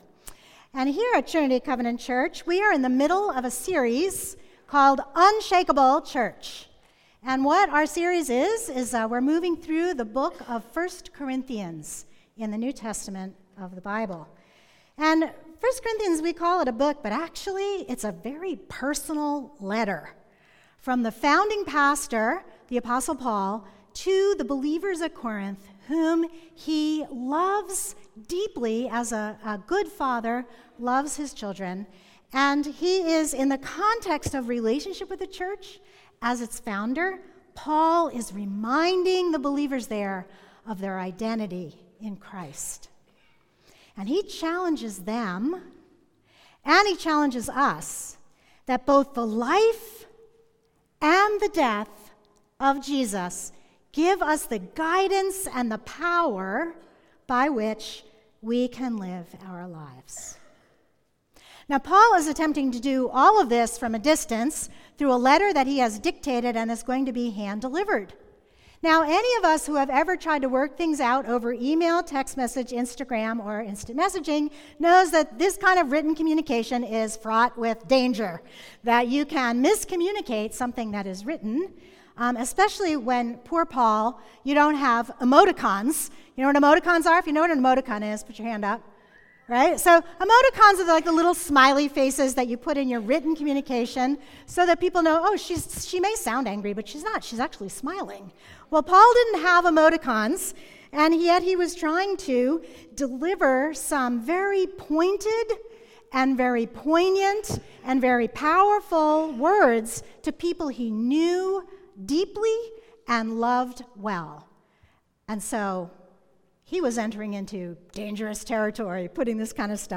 Messages